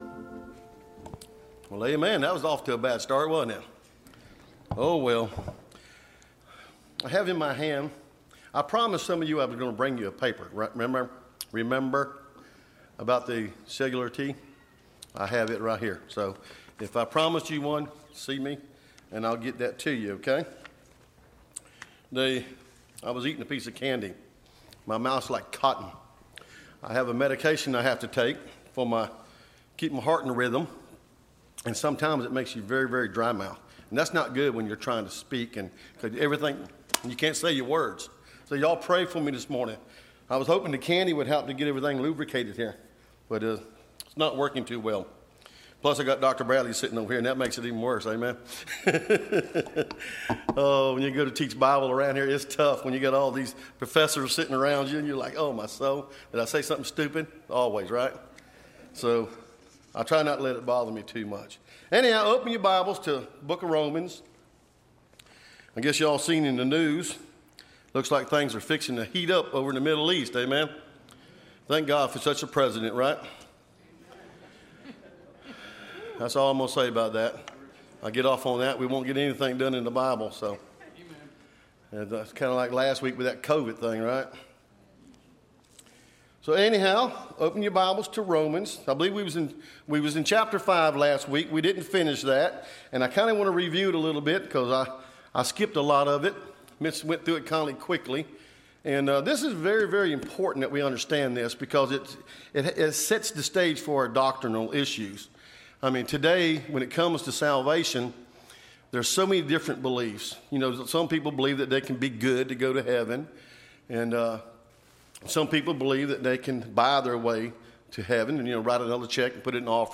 Listen to Message
Service Type: Sunday School